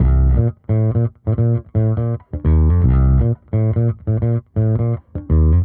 Index of /musicradar/dusty-funk-samples/Bass/85bpm
DF_JaBass_85-C.wav